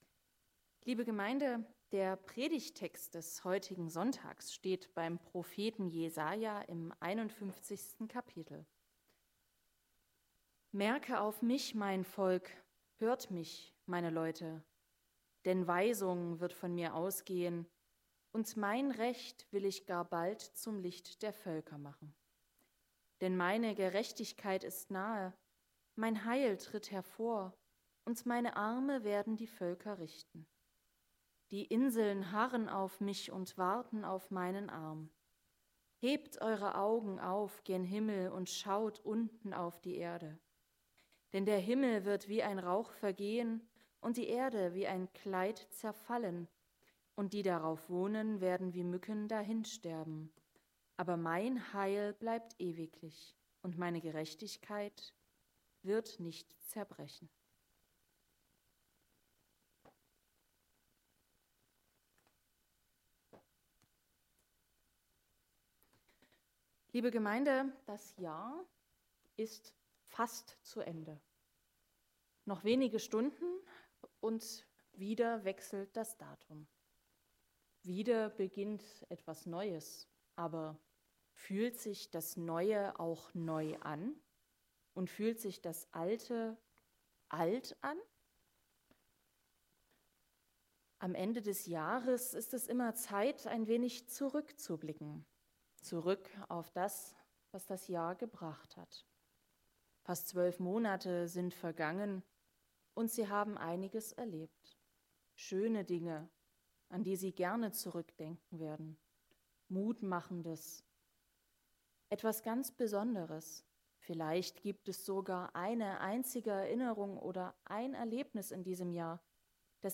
4-6 Gottesdienstart: Predigtgottesdienst Obercrinitz 2024 ist vorbei es beginnt etwas Neues.